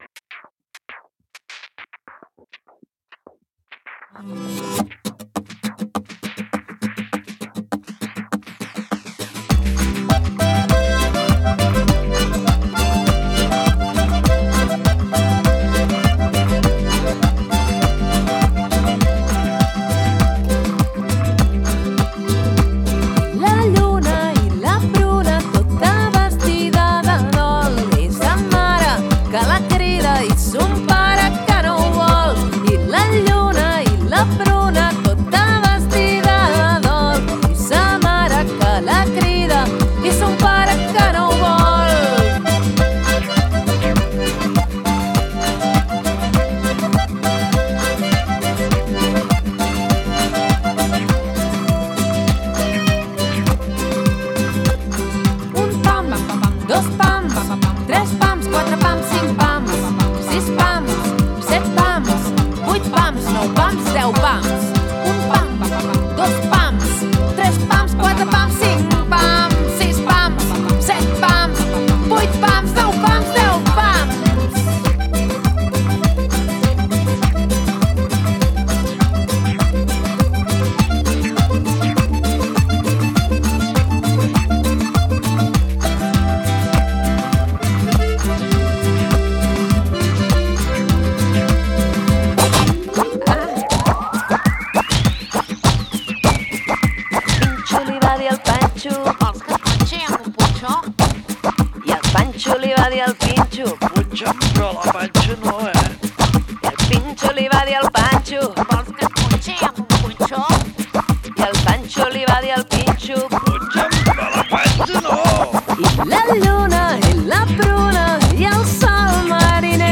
A Llenguatge musical treballarem la cançó: La lluna, la pruna